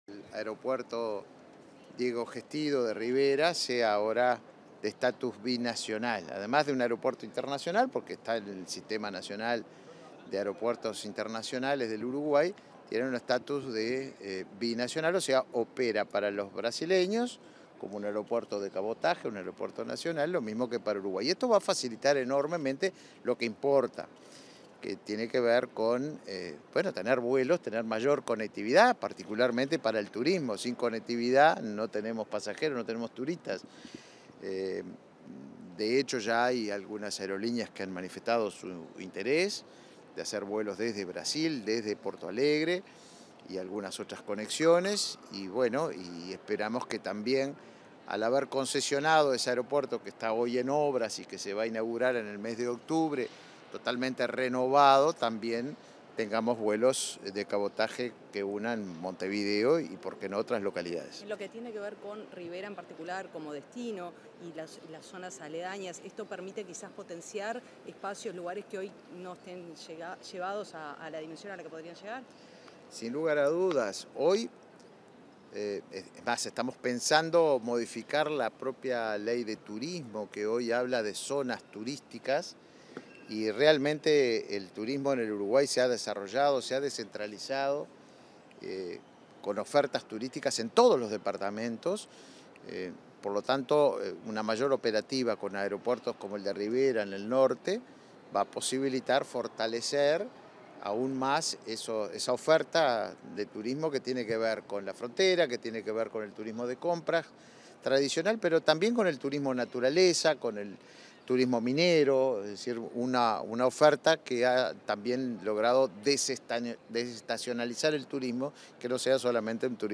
Declaraciones del ministro de Turismo, Tabaré Viera | Presidencia
Declaraciones del ministro de Turismo, Tabaré Viera 14/08/2023 Compartir Facebook Twitter Copiar enlace WhatsApp LinkedIn Tras la firma de la declaración conjunta de binacionalidad del aeropuerto de Rivera, este 14 de agosto, el ministro de Turismo, Tabaré Viera, realizó declaraciones a la prensa.